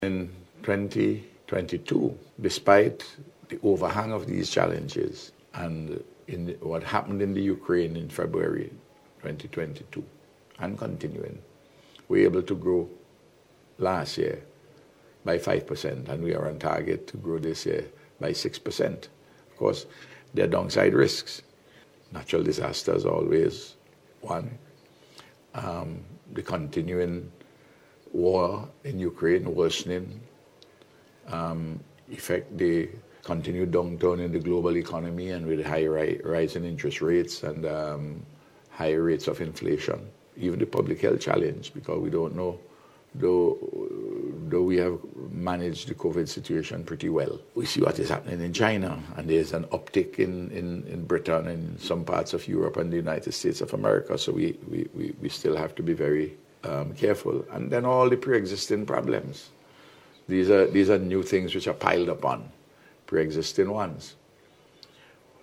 That is according to Prime Minister Dr. Ralph Gonslaves while speaking on a number of issues on the Round Table Talk aired on VC3 television last evening.